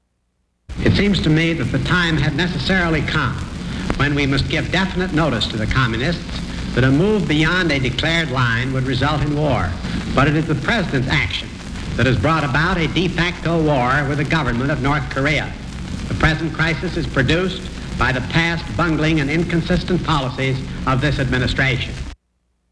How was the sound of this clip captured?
Broadcast 1950 July.